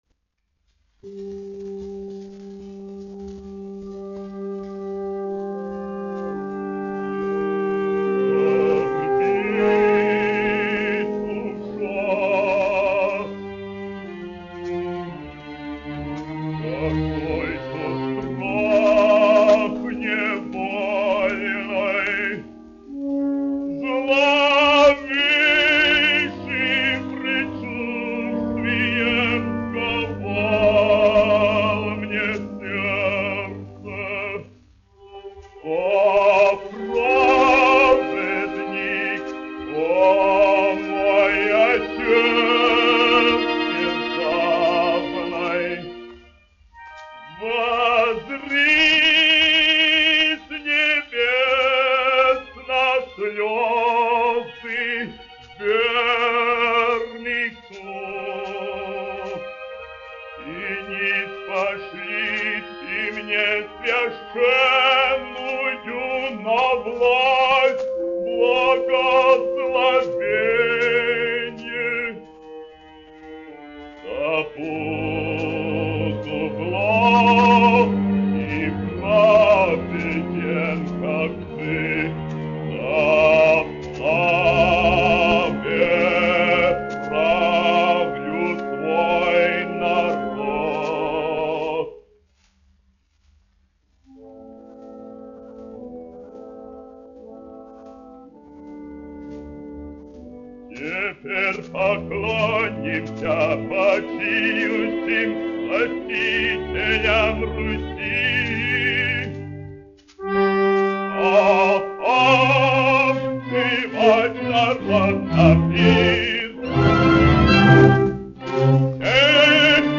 1 skpl. : analogs, 78 apgr/min, mono ; 25 cm
Operas--Fragmenti
Skaņuplate
Latvijas vēsturiskie šellaka skaņuplašu ieraksti (Kolekcija)